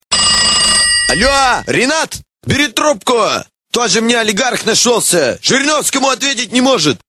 Категория: Рингтоны пародии